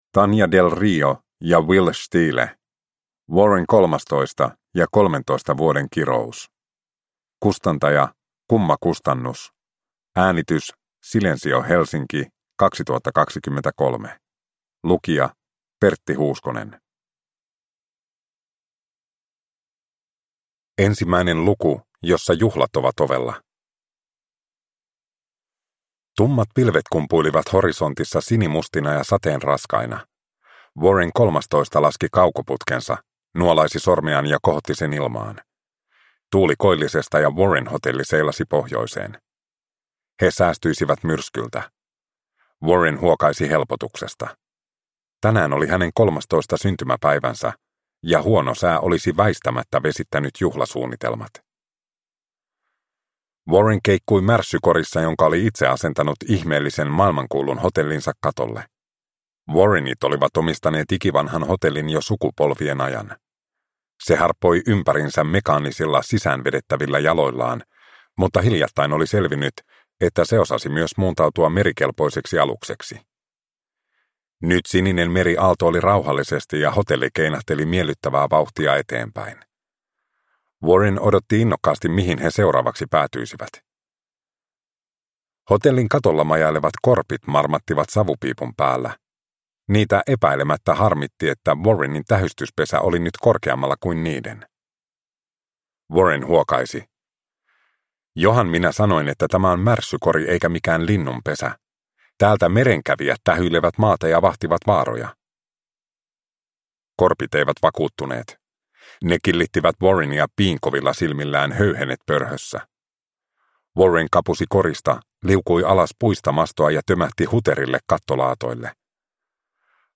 Warren 13. ja kolmentoista vuoden kirous – Ljudbok – Laddas ner